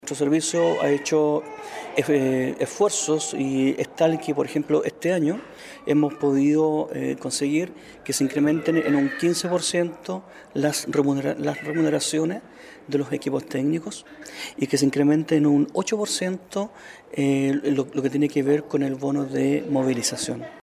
El Director regional de INDAP, Enrique Santis en diálogo con Radio Sago sobre algunas demandas efectuadas por personal del Programa de Desarrollo Agrícola  Prodesal en la región de Los Lagos y aprovechó la oportunidad para aclarar que este es un programa que tiene INDAP en convenio con los diferentes municipios, compuesto por personal a honorarios contratados por las propias casas ediles gracias al traspaso de recursos.